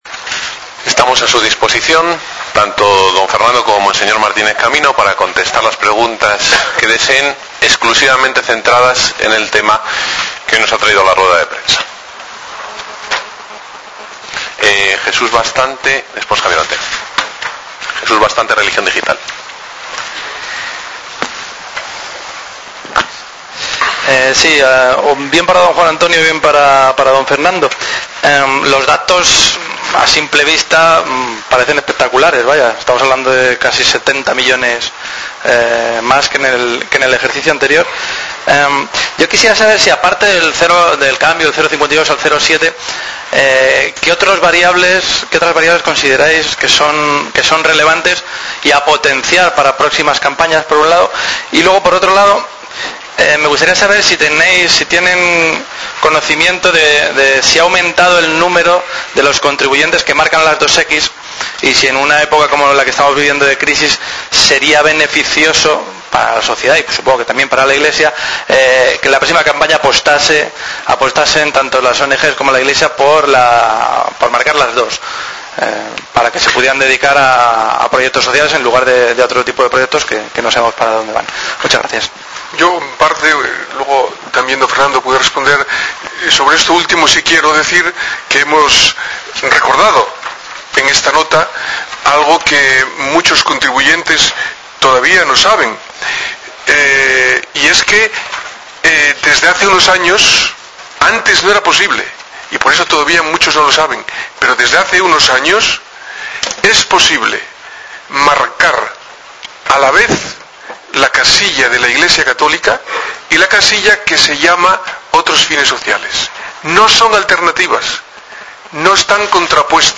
Descargar Audio de la Rueda de prensa